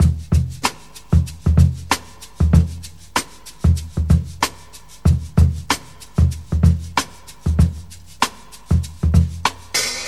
• 95 Bpm Modern Breakbeat Sample E Key.wav
Free breakbeat - kick tuned to the E note. Loudest frequency: 1054Hz
95-bpm-modern-breakbeat-sample-e-key-MMU.wav